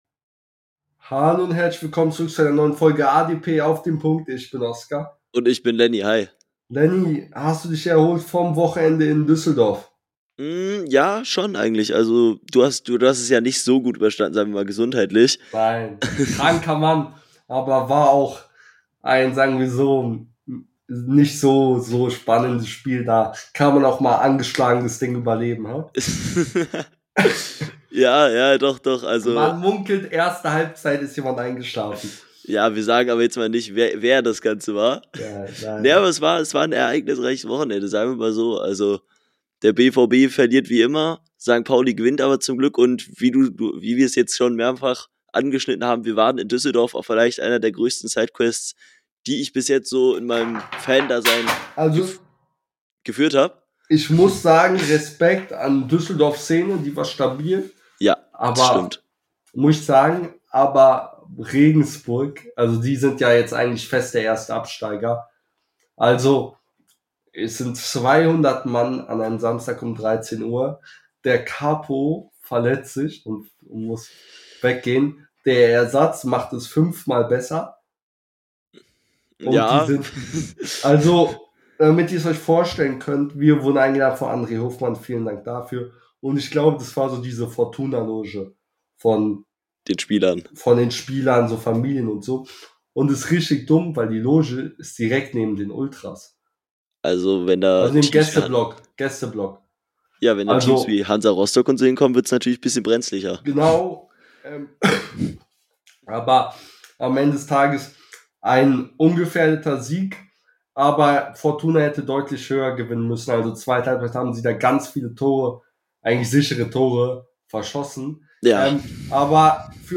in der heutigen Folge reden die beiden Hosts über den Aufstiegskampf in Liga 2 , geben ihre Prognose ab ,freuen sich mit der Herta und vieles mehr